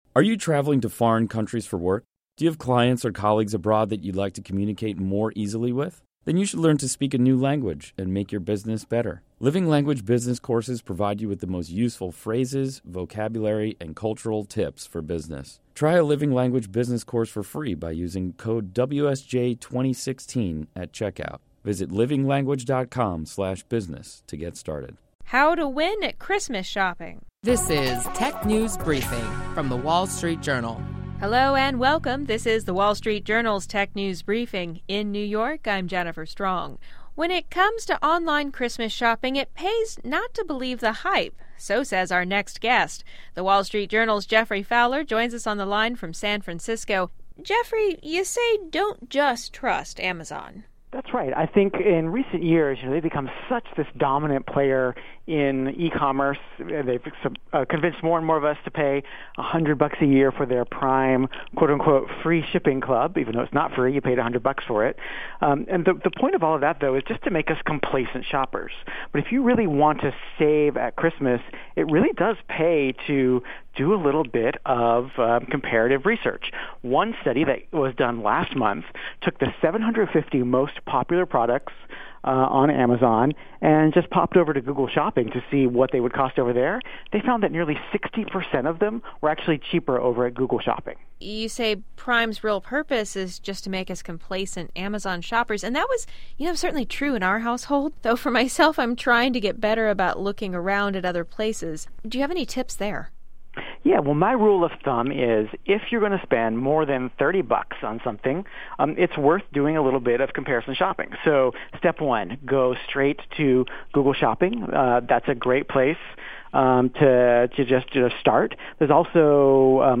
Stay informed on the latest technology trends with daily insights on what’s hot and happening in the world of technology. Listen to our WSJD reporters discuss notable tech company news, new tech gadgets, personal technology updates, app features, start-up highlights and more.